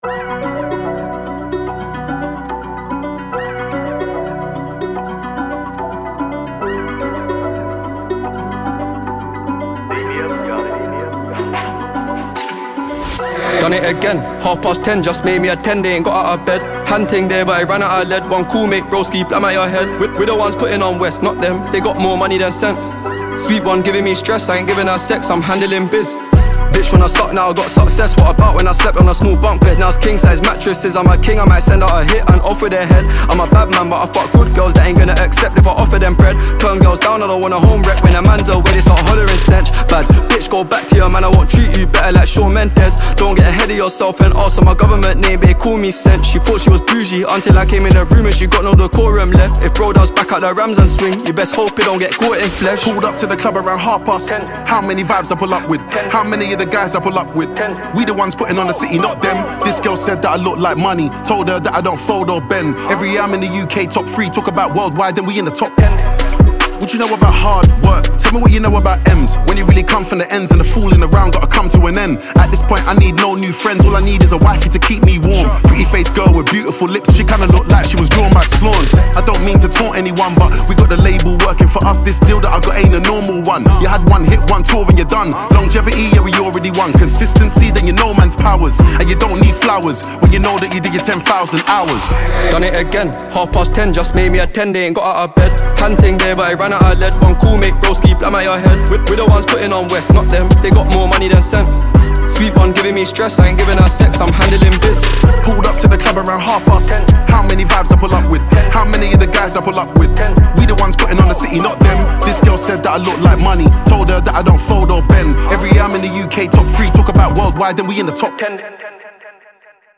Sensational Talented UK Drill recorder and singer